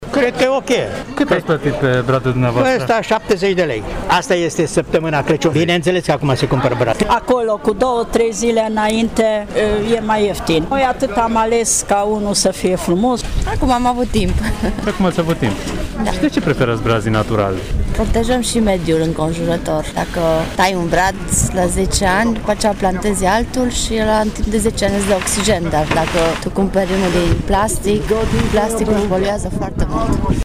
Mureșenii sunt mulțumiți de prețul brazilor din acest an și spun că au așteptat până acum fie din lipsă de timp, fie pentru că au sperat că vor fi reduceri pe ultima sută de metri :